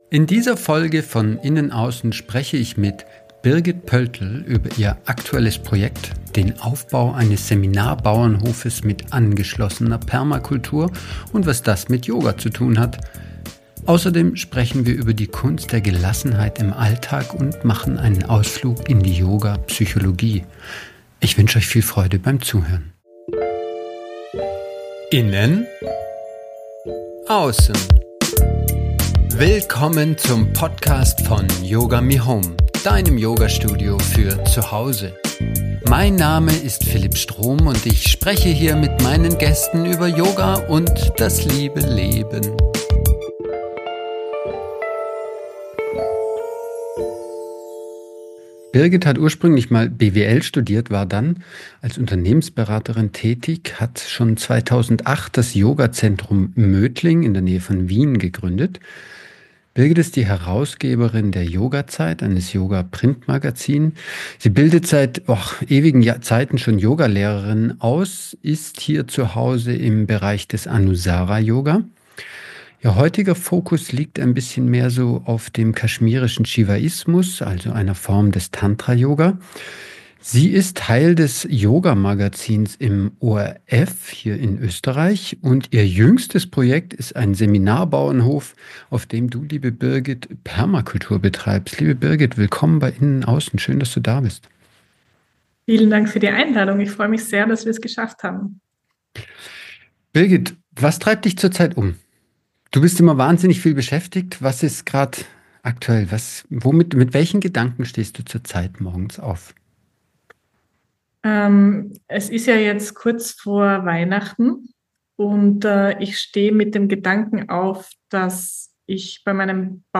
Ein Gespräch über Yoga-Psychologie, Permakultur und die Kraft der Gelassenheit.